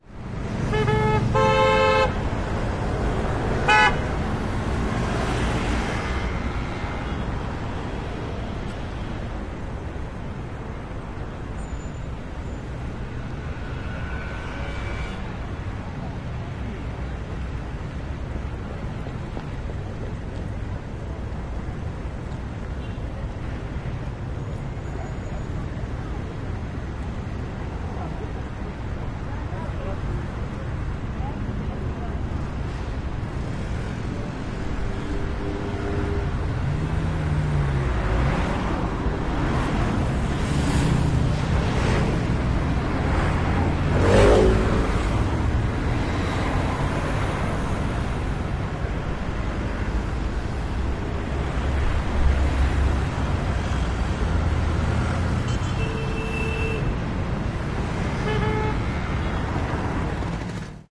周边环境 Placa Francesc Macia